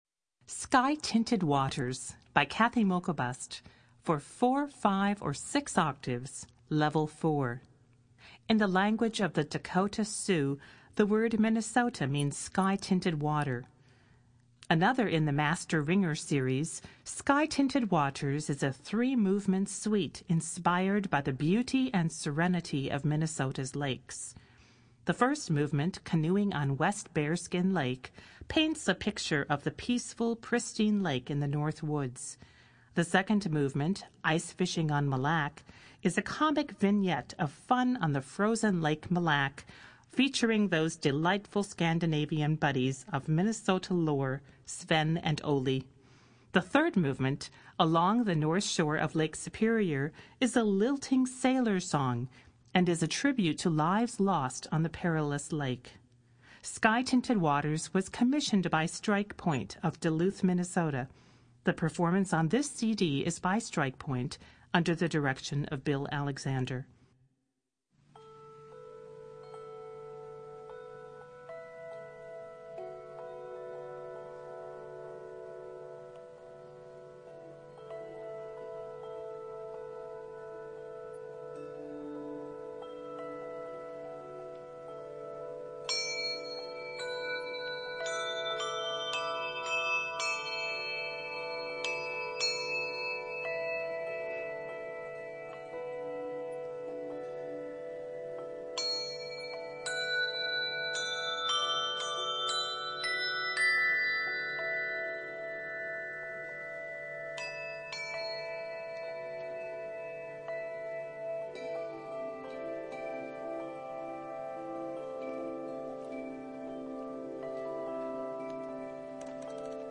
Voicing: Handbells 4-6 Octave